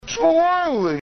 toilet-slow.mp3